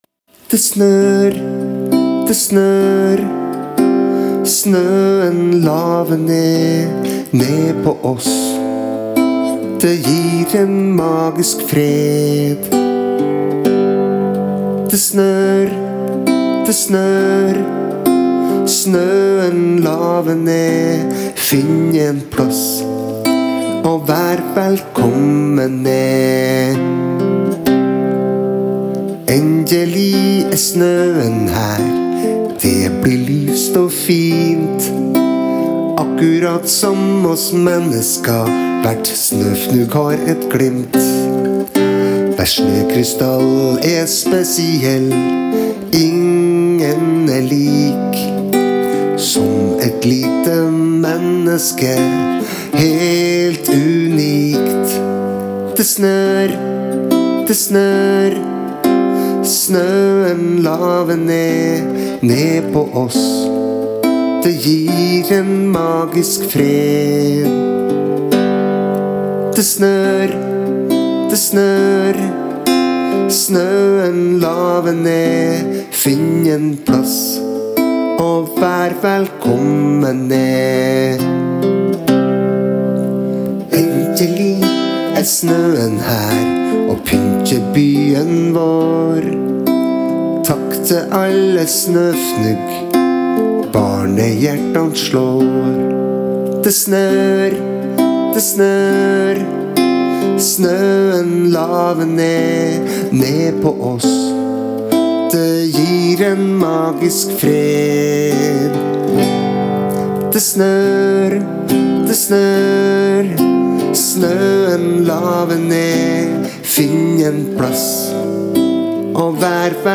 Lørdag 13. november 2021: DET SNØR – BARNESANG (Sang nr 73 – på 73 dager)